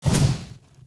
Torch Attack Strike 1.ogg